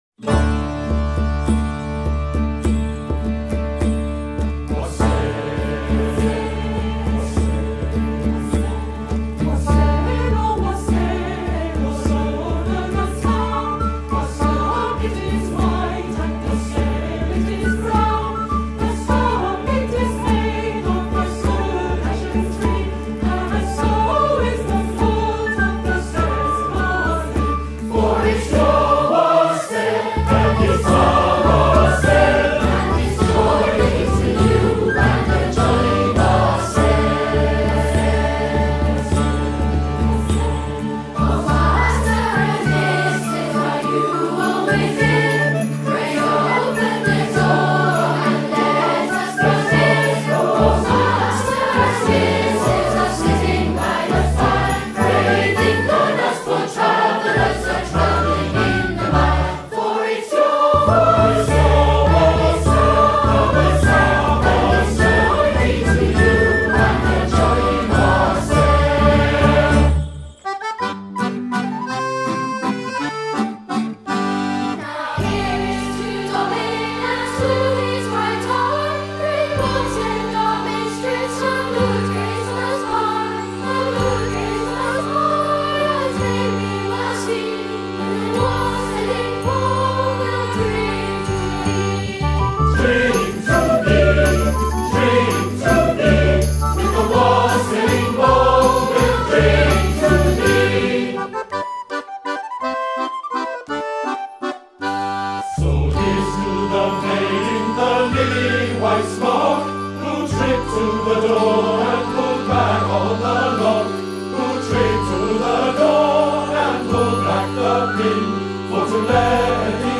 wassail-medley.mp3